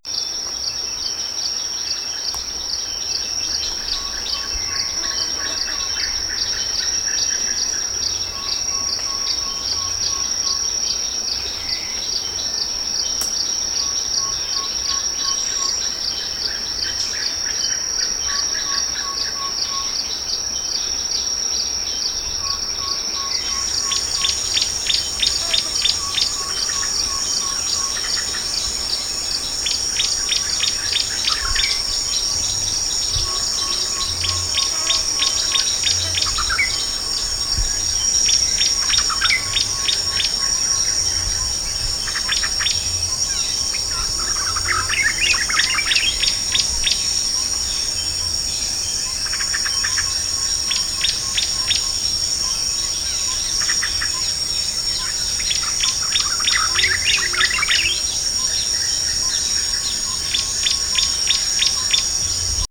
• tropical rainforest ambient.wav
tropical_rainforest_ambient_eou.wav